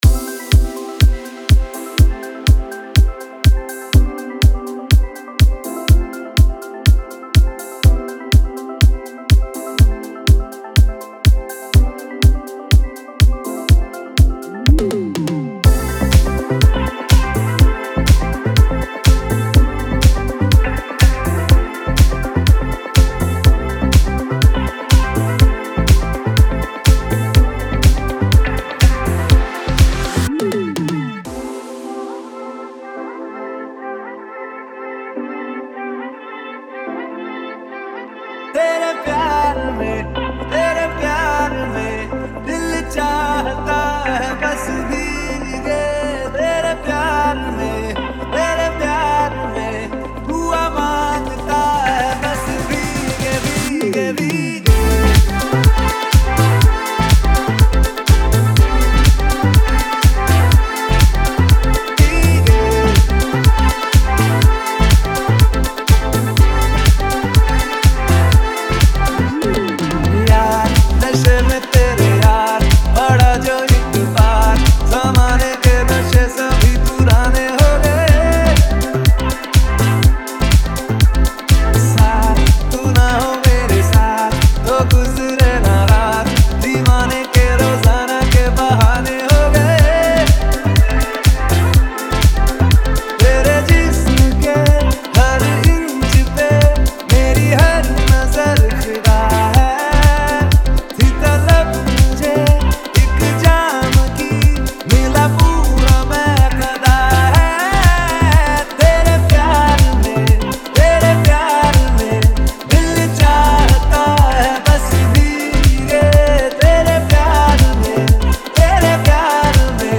Bollywood Deep House
Bollywood DJ Remix Songs